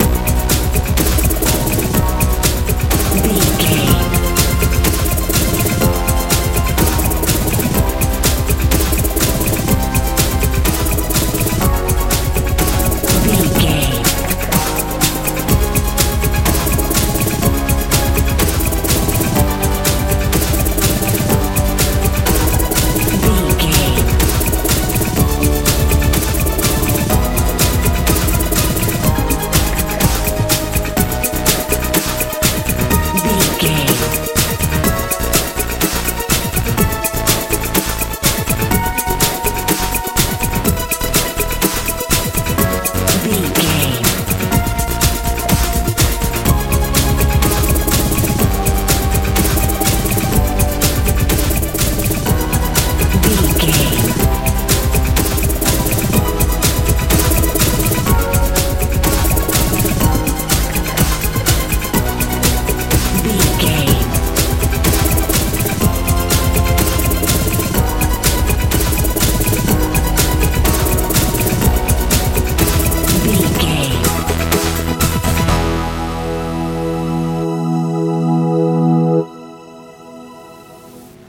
euro dance feel
Ionian/Major
futuristic
powerful
synthesiser
bass guitar
drums
suspense
tension